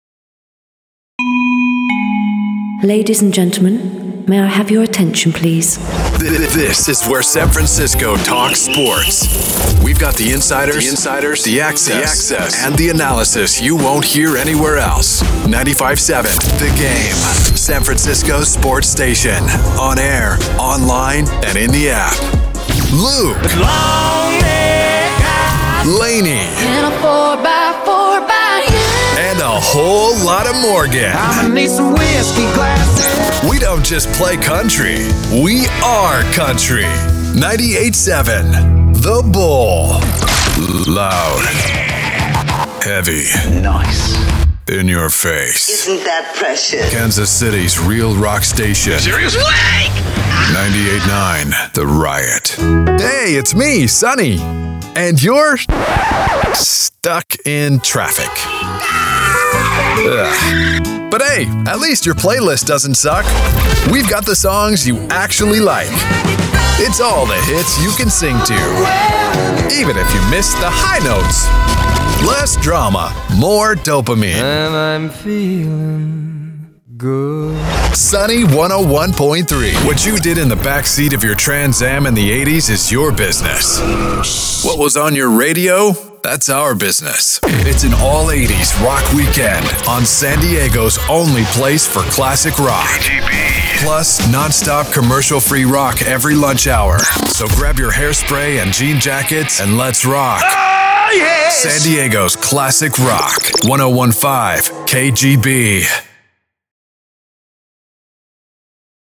A deep, rich, gritty male voice talent for commercials and narration
Radio Imaging Demo
Radio Imaging Demo_sports first.mp3